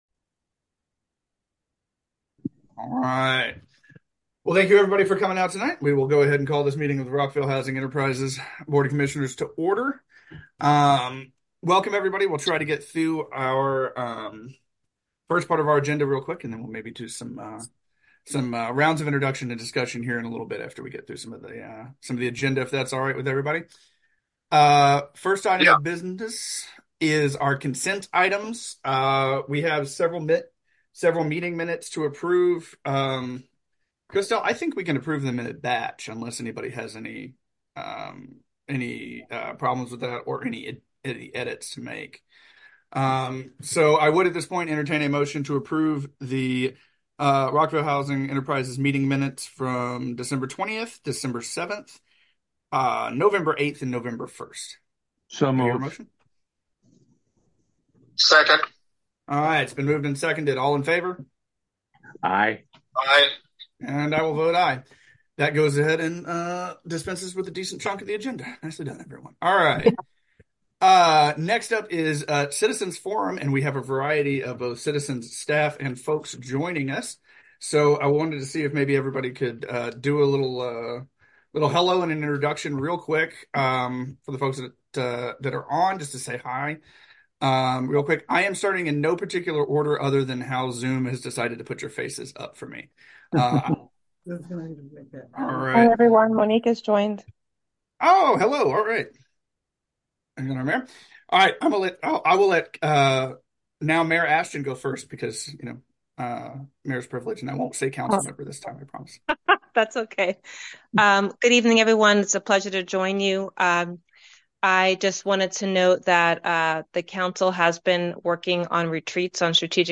1.24-Meeting-mins.mp3